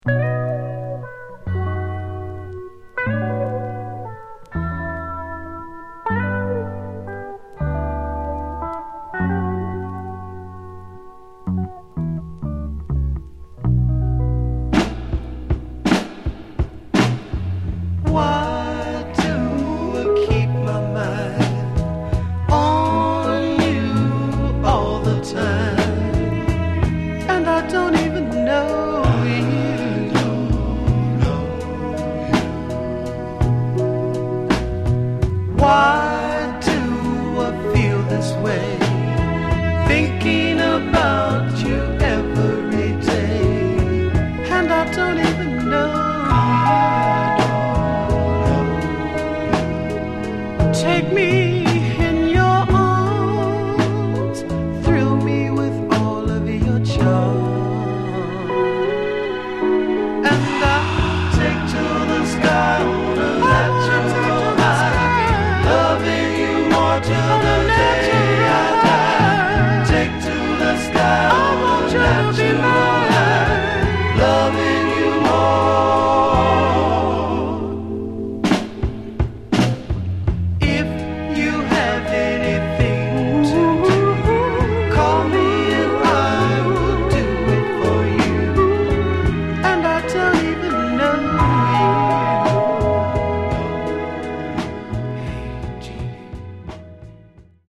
Genre: Northern Soul, Philly Style